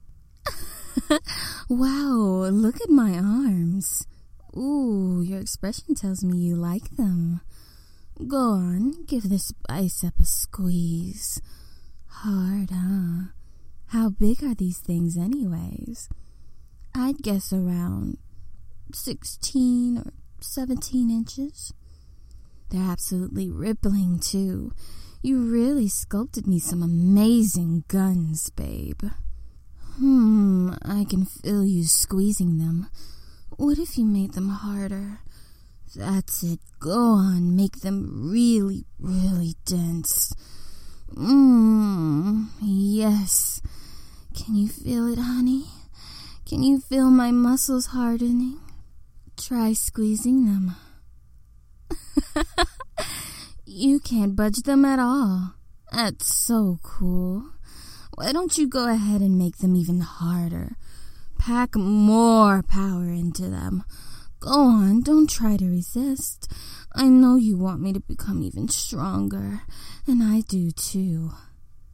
Muscle Talk – No Effects Bass Level 2
Muscle-Sample-No-Effects-Bass-2.mp3